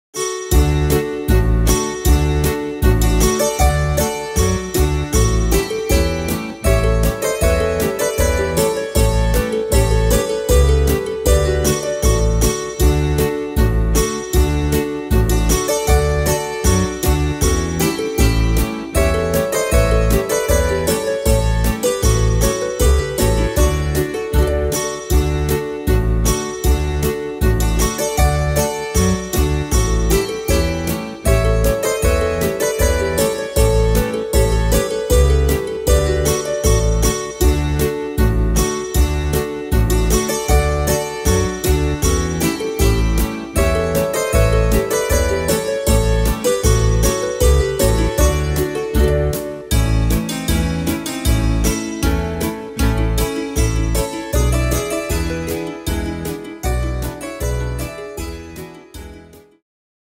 Tempo: 78 / Tonart: D-Dur